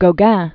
(gō-găɴ), (Eugène Henrí) Paul 1848-1903.